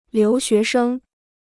留学生 (liú xué shēng): student studying abroad; (foreign) exchange student.